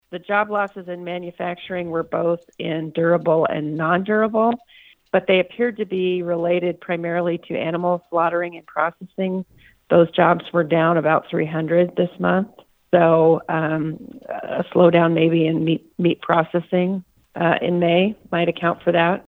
Iowa Workforce Development director Beth Townsend says job losses were offset by more workers joining the labor force.
Townsend says there was one manufacturing area that saw the bulk of the losses.